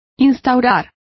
Complete with pronunciation of the translation of establish.